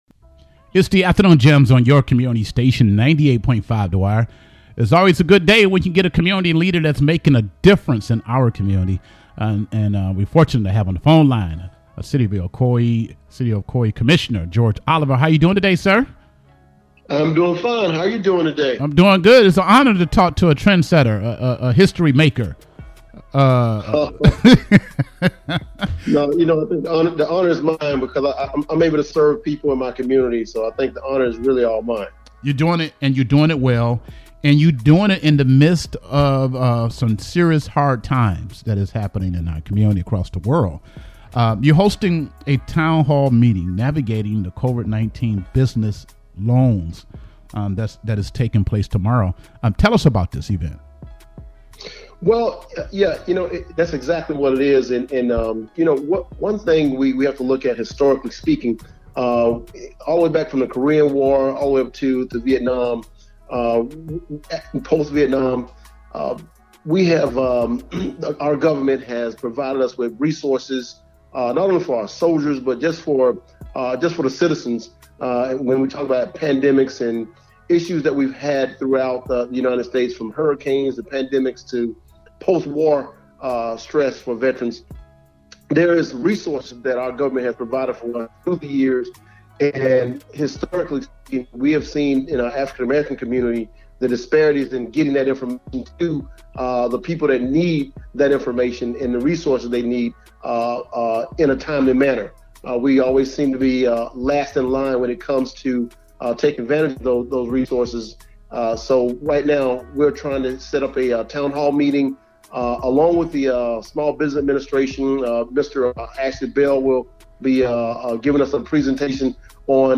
The First Black City Commissioner for the City of Ocoee George Oliver joined the conversation today on the Afternoon Jamz to discuss the upcoming COVID-19 Business Loans Town Hall Meeting being held on Thursday April 16.